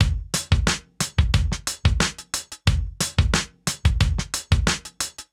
Index of /musicradar/sampled-funk-soul-samples/90bpm/Beats
SSF_DrumsProc2_90-02.wav